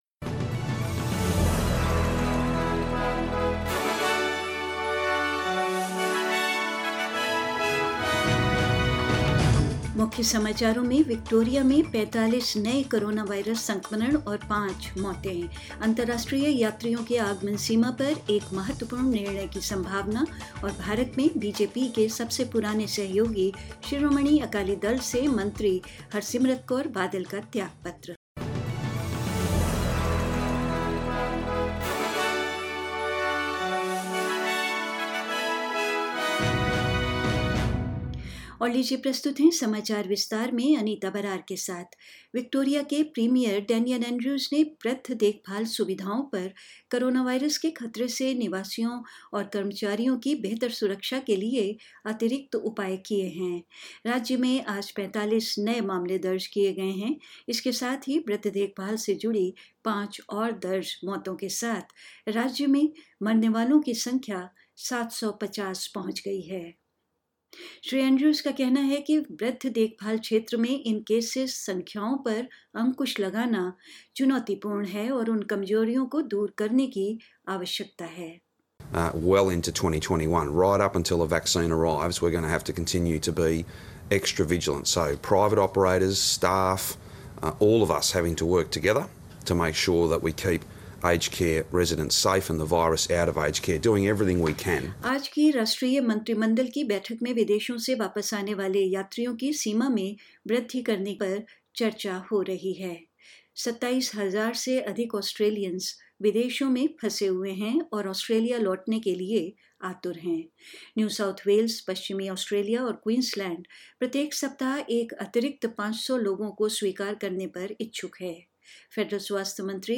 In this bulletin...** More Australians stranded overseas are set to return, after states and territories agree to increase their hotel quarantine caps...** The Prime Minister announces an extension of telehealth support services in Australia...** India added over 96K coronavirus in 24 hours... and more news